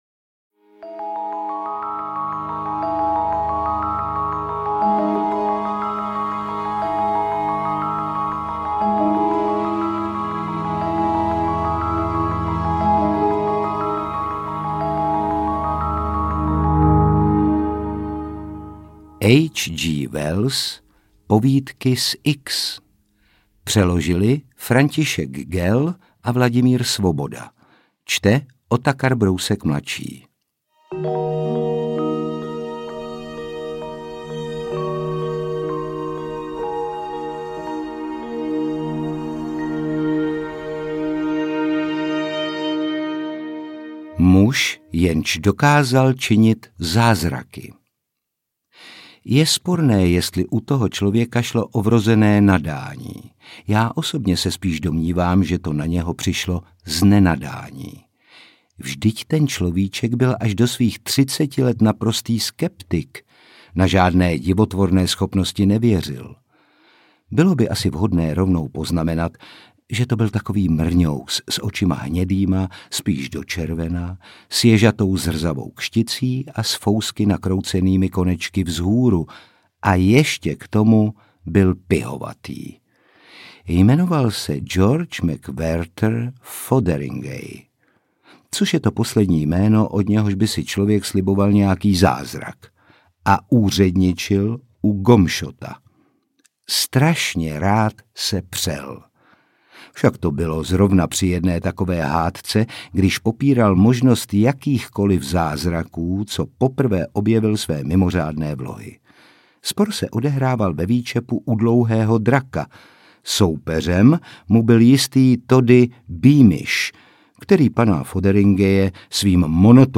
Interpret:  Otakar Brousek ml.
AudioKniha ke stažení, 17 x mp3, délka 7 hod. 26 min., velikost 407,0 MB, česky